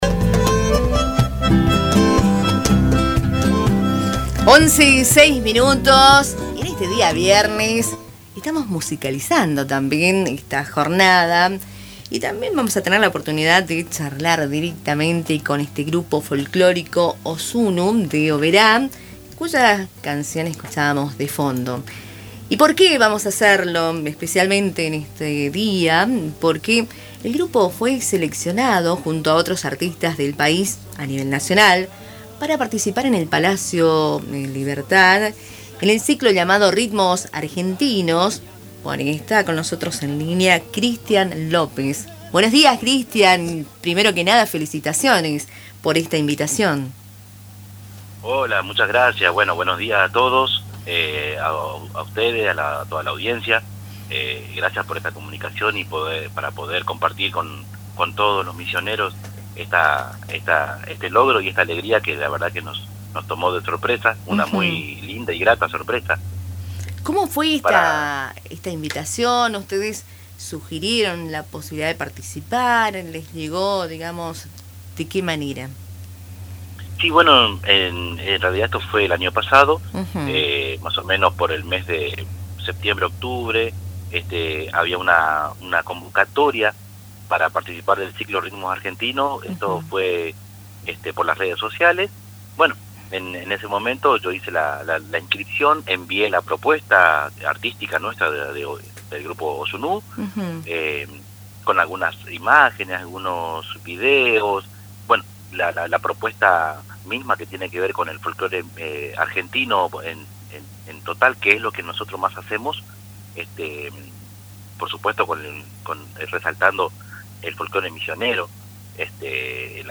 durante una entrevista con Radio Tupambaé.